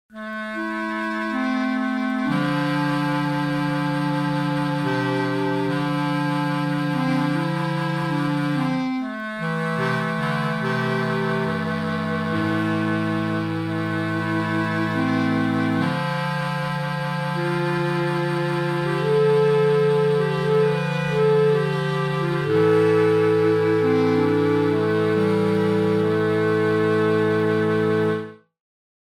Chamberlin Bass Clarinet
This one is very similar to the Mellotron bass clarinet and therefore there is hardly more to add to the original. This one sounds a little more 'metalic' and in tight clusters sounds less dense than the other whilst at the same time rattling like an harmonium having a bad day. Another beast of a noise guaranteed to break the ice at parties.
ch-bassclarinet.mp3